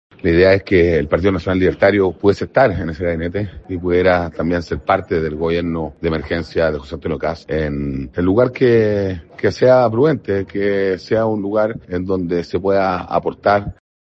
Ante esta situación, el diputado libertario Cristián Labbé lamentó que esto no se haya concretado.